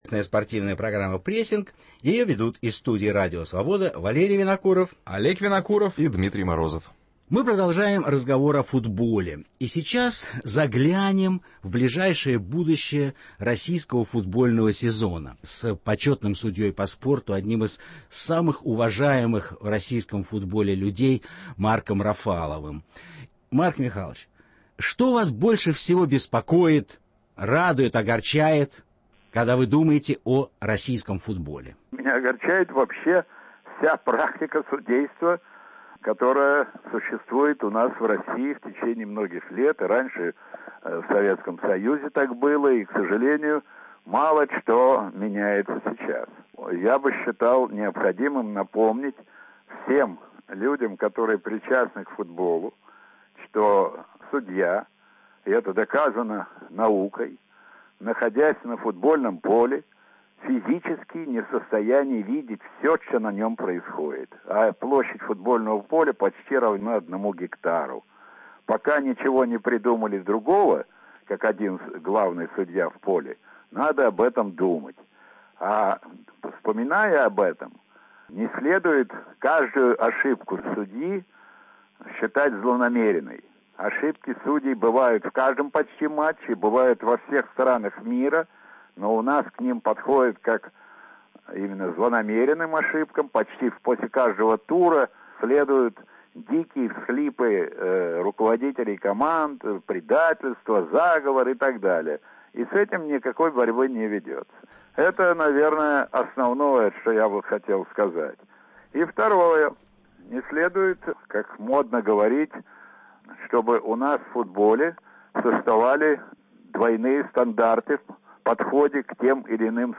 Интервью с Марком Рафаловым